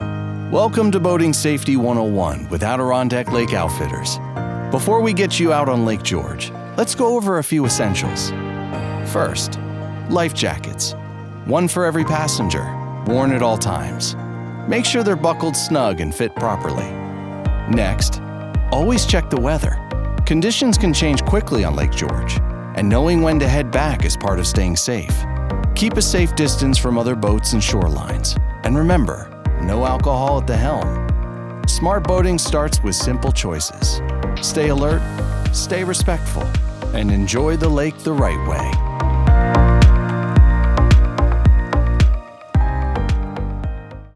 Clear · Warm · Trustworthy
An informative, responsible narration style ideal for public safety, government, and instructional content.